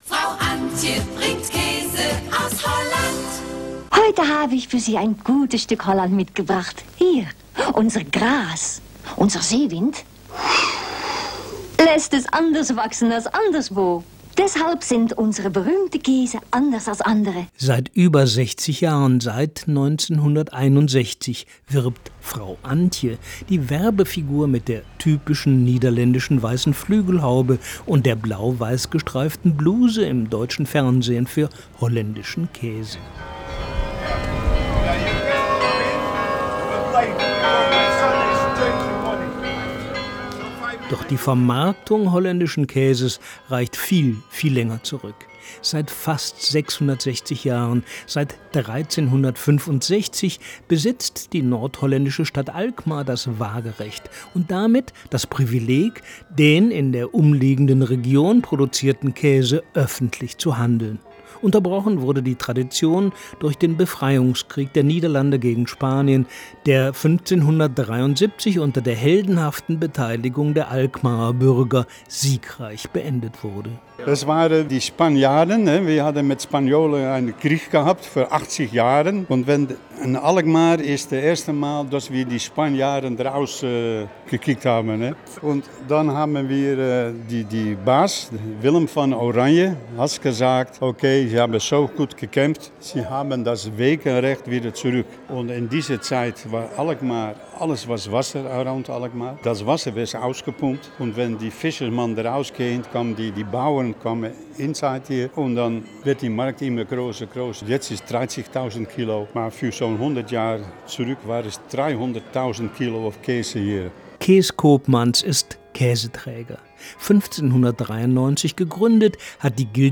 DLF-Sonntagsspaziergang-Kaesemarkt-Alkmaar.wav